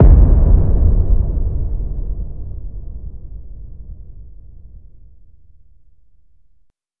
VEC3 FX Reverbkicks 11.wav